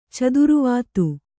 ♪ caduruvātu